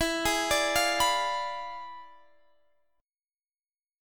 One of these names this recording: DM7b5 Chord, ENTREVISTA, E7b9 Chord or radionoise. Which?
E7b9 Chord